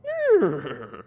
horsy-take.mp3